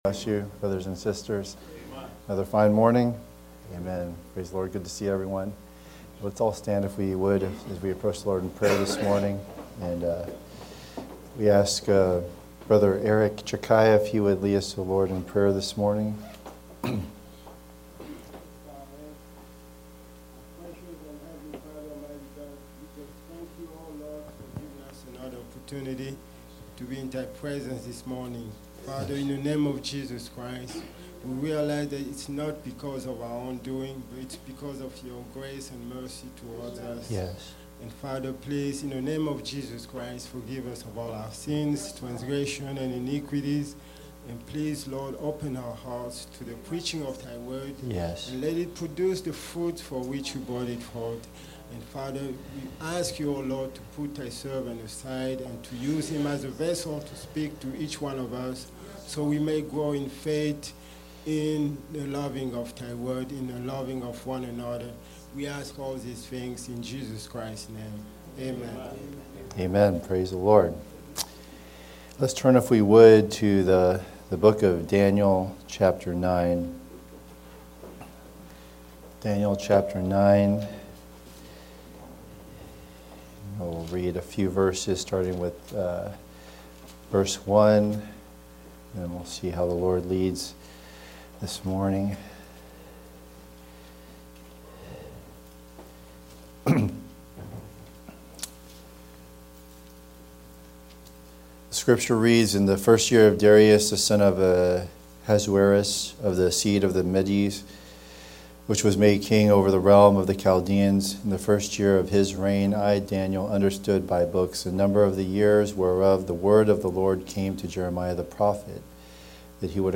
Fulfilling the Commission – Love in Desperation (Sunday School)